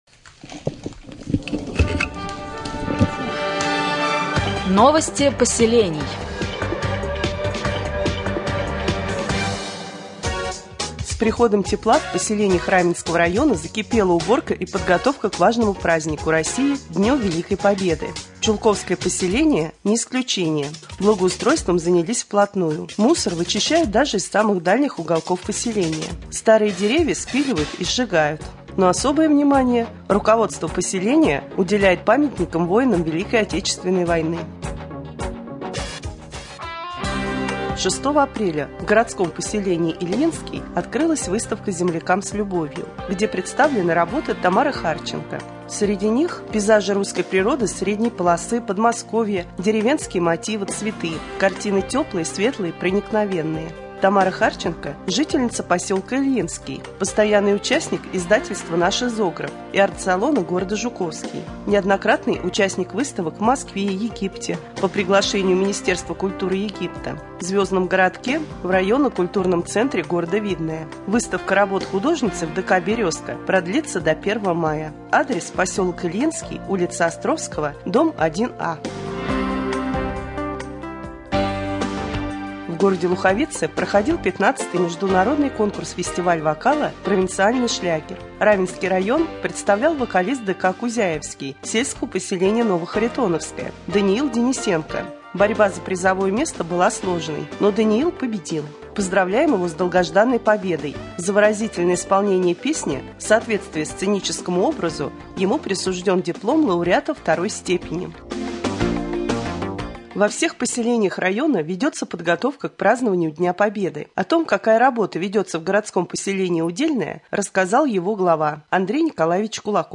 2. Новости поселений